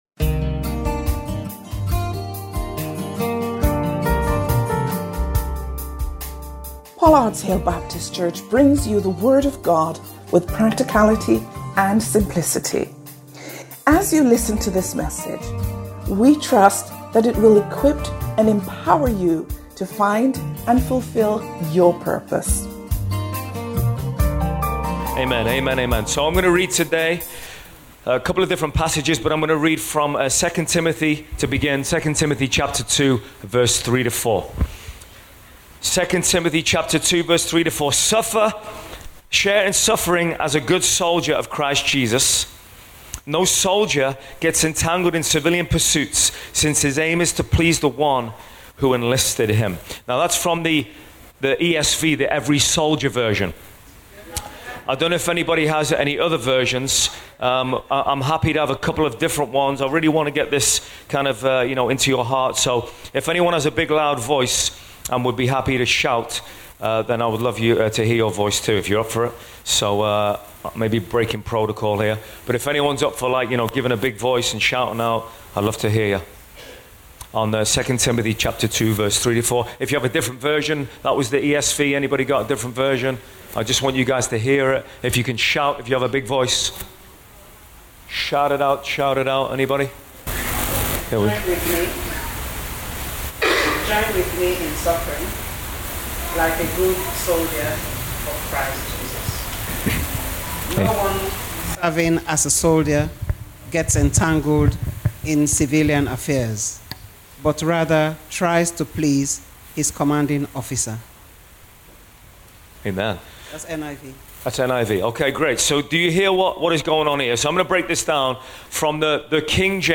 Watch this service and more on our YouTube channel – CLICK HERE